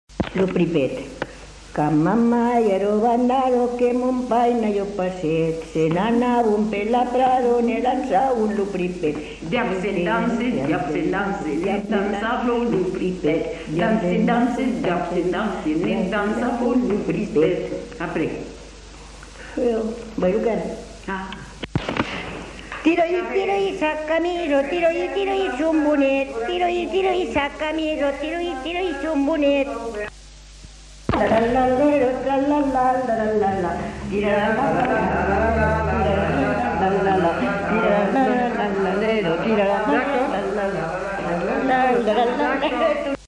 Aire culturelle : Haut-Agenais
Lieu : Cancon
Genre : chant
Effectif : 2
Type de voix : voix de femme
Production du son : chanté ; fredonné
Danse : pripet